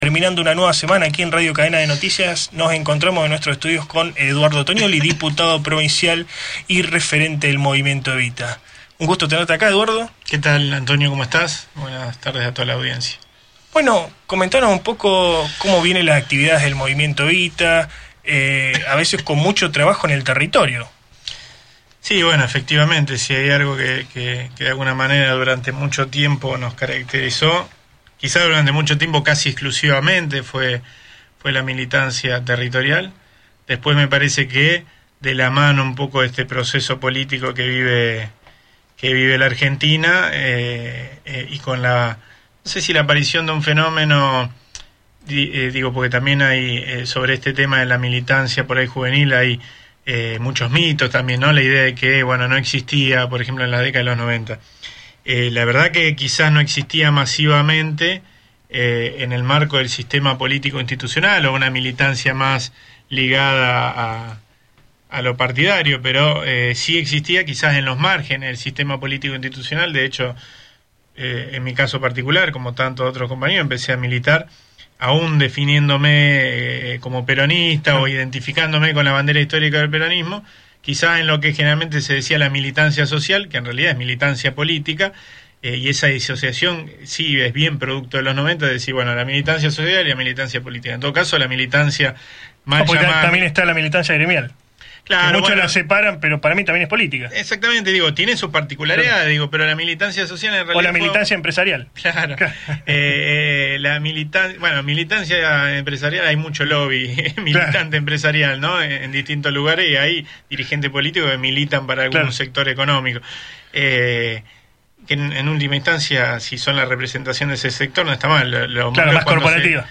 EDUARDO TONIOLLI AUDIO ENTREVISTA
Radio Cadena de Noticias, programa que se emite de lunes a viernes de 18 a 19 hs por AM Libertad 1090, entrevistó al diputado provincial del Movimiento Evita y Presidente de la Comisión de Derechos y Garantías de la Legislatura,Eduardo Toniolli.